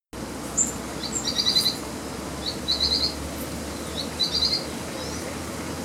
Pijuí Plomizo (Synallaxis spixi)
Nombre en inglés: Spix´s Spinetail
Fase de la vida: Adulto
Localidad o área protegida: Reserva Ecológica Costanera Sur (RECS)
Condición: Silvestre
Certeza: Vocalización Grabada